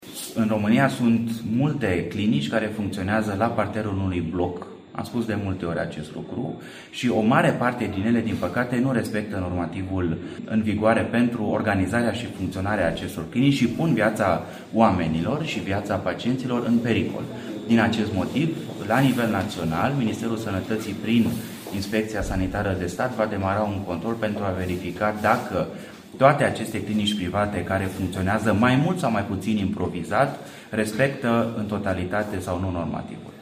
Inspecția Sanitară de Stat va începe o serie de controale în clinicile private din întreaga țară pentru a se vedea dacă aceste instituții sanitare respectă normativele legale în vigoare – a anunțat ministrul Alexandru Rogobete, la Vaslui.